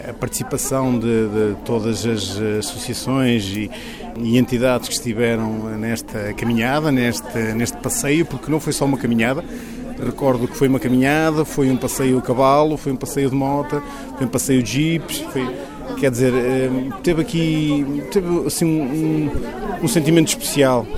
O presidente da Câmara Municipal de Macedo de Cavaleiros, Sérgio Borges, sublinha que o objetivo foi exatamente juntar pessoas em torno de uma causa solidária, no âmbito da programação natalícia do município: